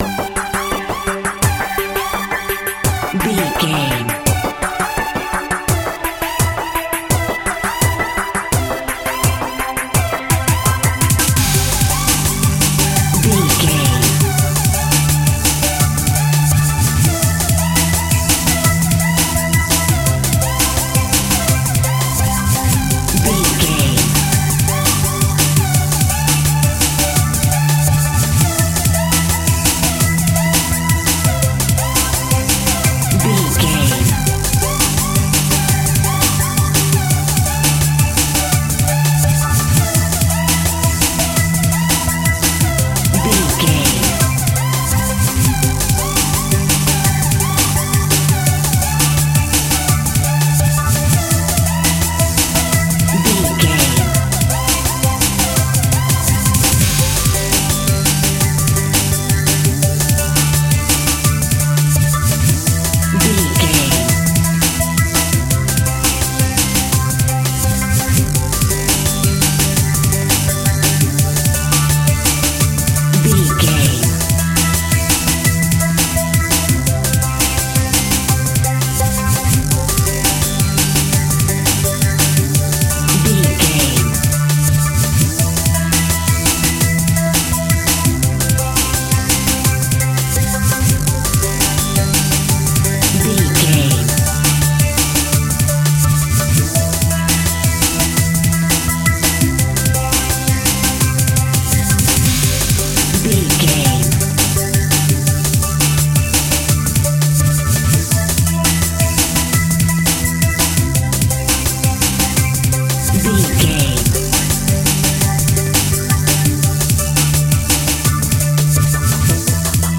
Ionian/Major
Fast
groovy
uplifting
futuristic
driving
energetic
repetitive
drums
synthesiser
drum machine
electronic
instrumentals
synth bass
synth lead
synth pad
robotic